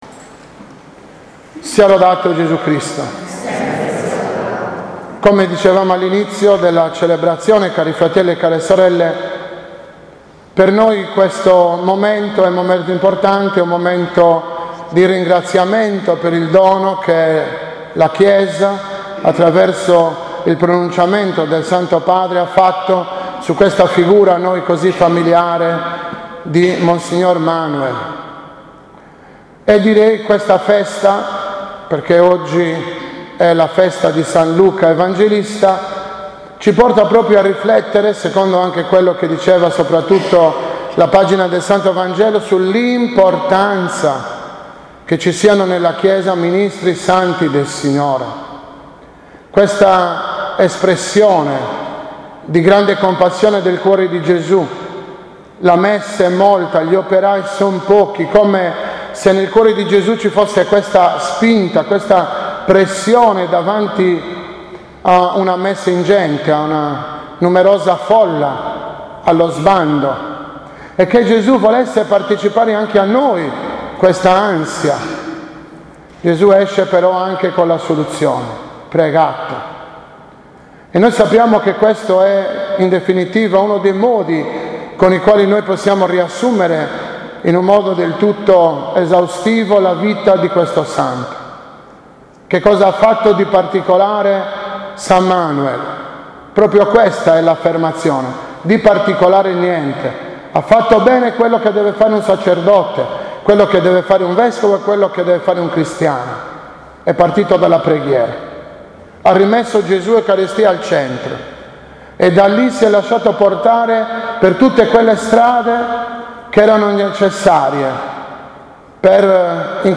13.05.2014 - OMELIA DELLA S. MESSA IN MEMORIA DELLA B.V. MARIA DI FATIMA